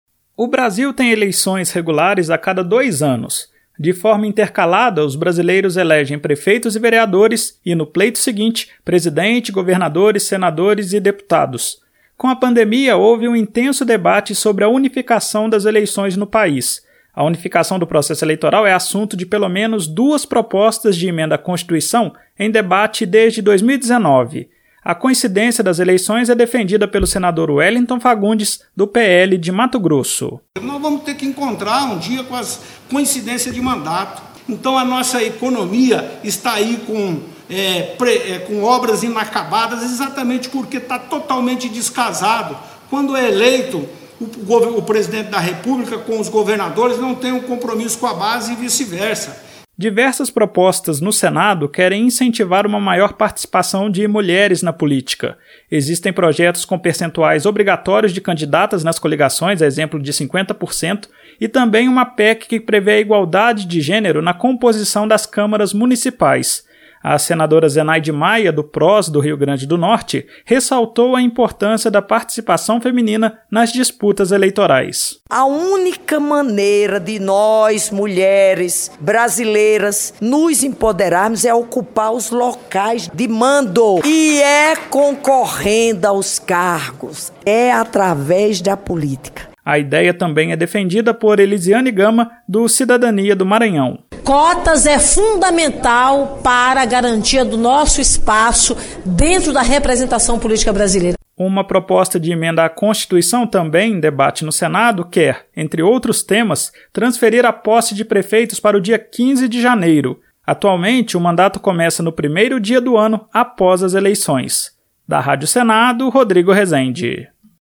Senadoras como Eliziane Gama (Cidadania-MA) e Zenaide Maia (PROS-RN) defendem a adoção de cotas e maior participação feminina na política.